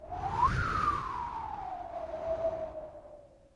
Tag: 创意 敢-19 循环 口技